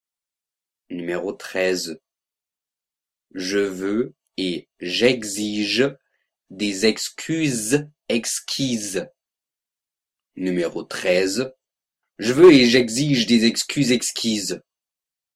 13 Virelangue